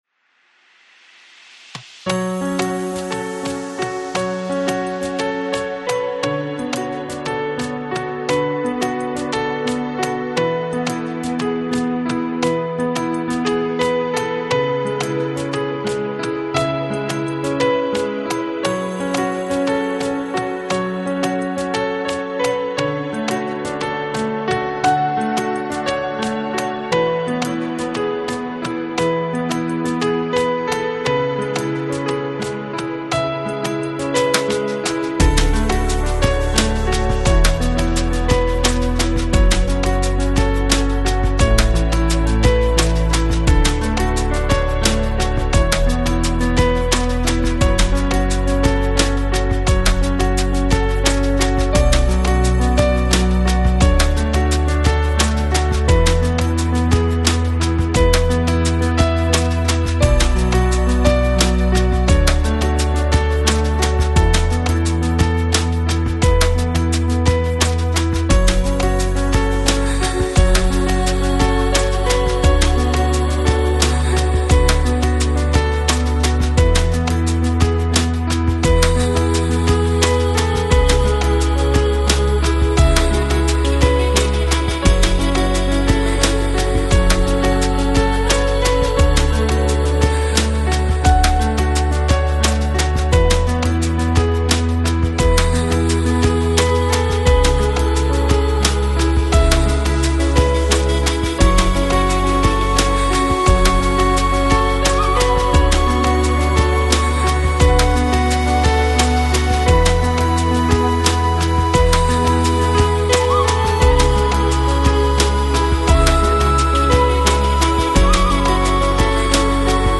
Жанр: Chillout
最初耳边传来的是鸟鸣啾啾，继而缥缈清灵的女声魅惑渐闻，心随之跟随乐曲激荡、舞动、飞翔一如曾经的生活高低起伏于眼前，
音乐的主导风格：新世纪、环境、驰放、沙发和混音类的天籁神曲。